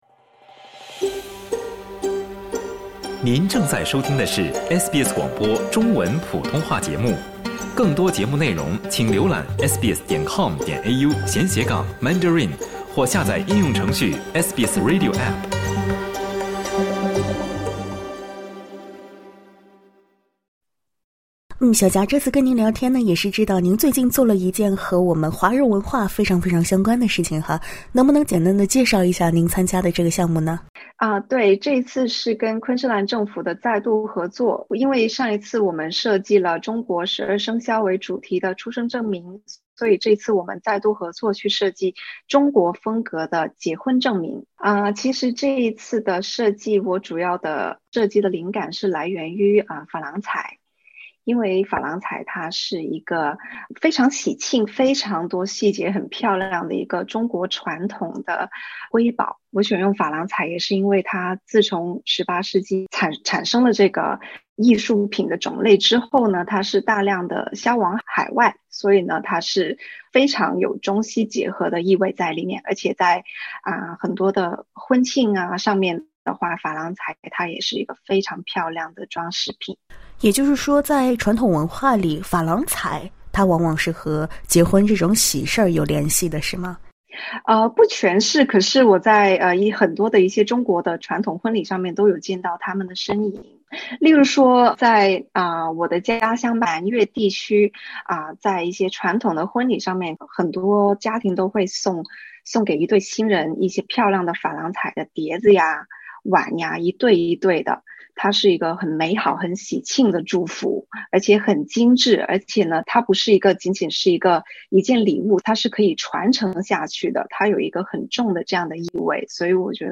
SBS 普通话电台 View Podcast Series Follow and Subscribe Apple Podcasts YouTube Spotify Download